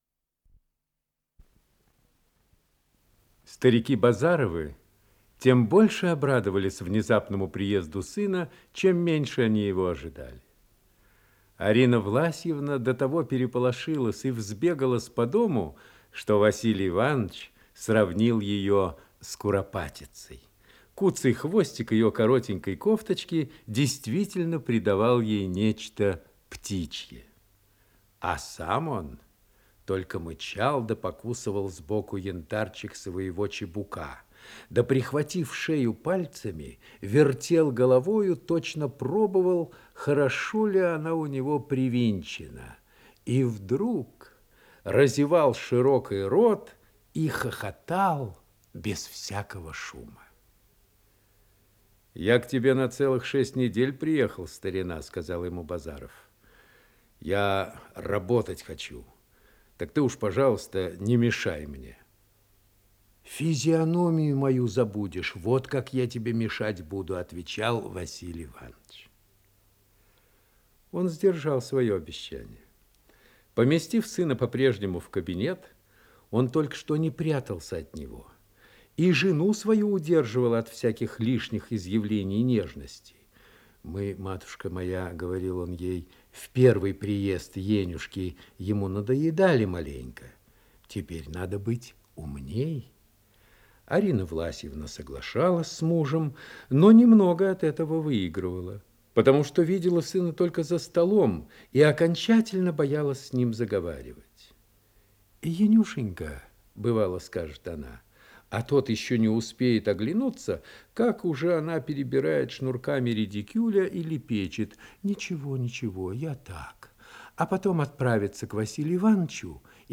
Исполнитель: Владимир Дружников - чтение
Литературное чтение